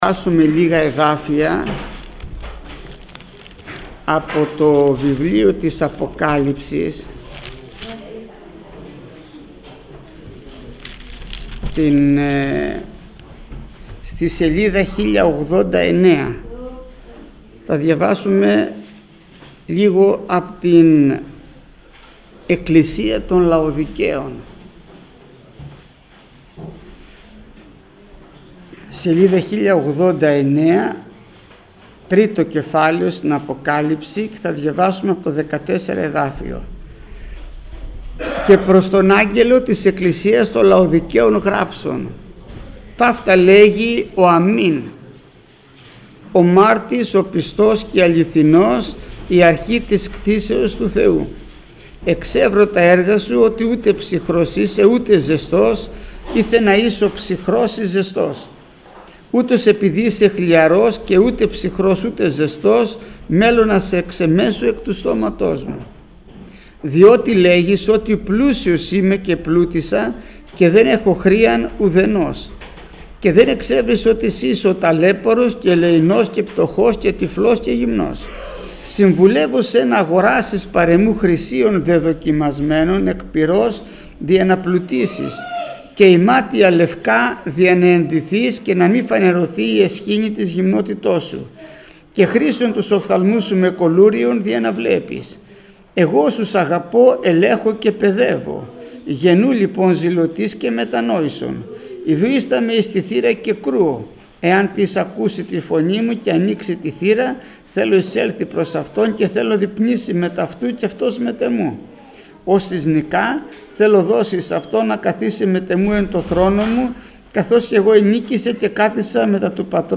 Μηνύματα Θείας Κοινωνίας